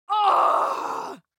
جلوه های صوتی
دانلود صدای دعوا 29 از ساعد نیوز با لینک مستقیم و کیفیت بالا